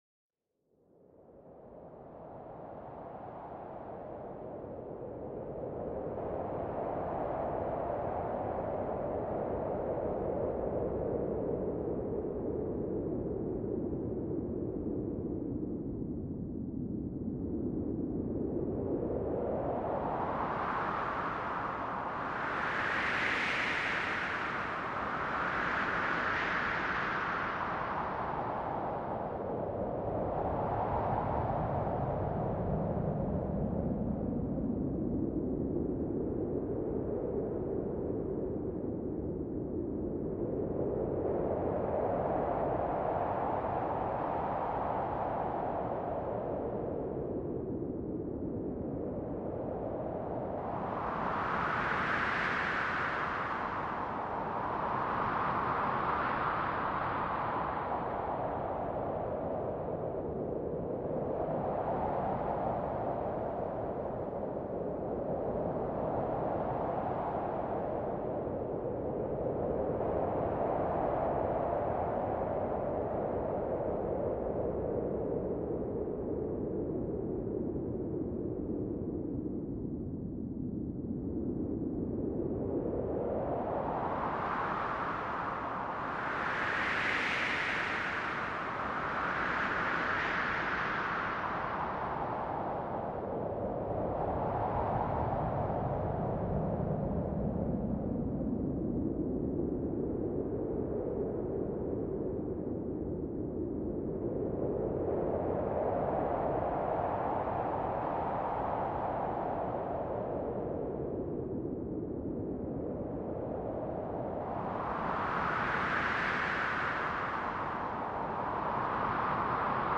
Tormenta de Viento para la Relajación: Deja que el Poderoso Aliento de la Naturaleza te Lleve
Sumérgete en la intensidad de una tormenta de viento, sintiendo cada ráfaga a tu alrededor. Este sonido cautivador te transportará al corazón de la naturaleza salvaje.
Cada episodio te sumerge en un ambiente sonoro relajante.